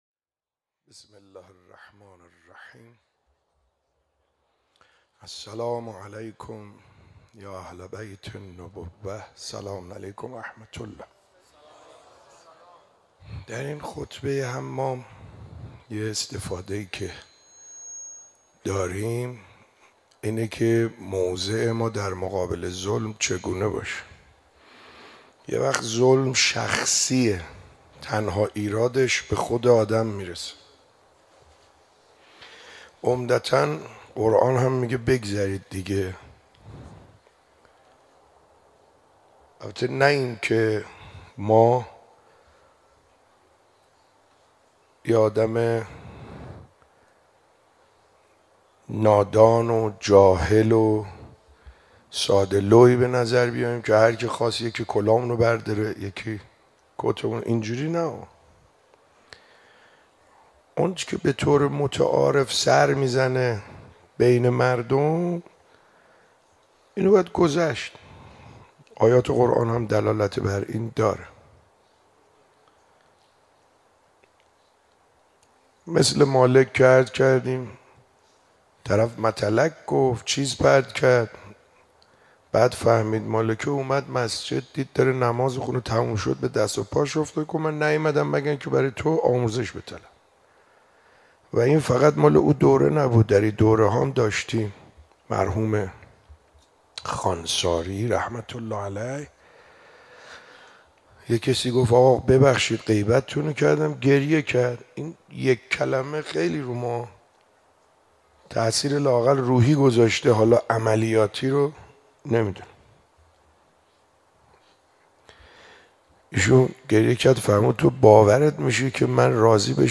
شب 22 ماه مبارک رمضان 95_صحبت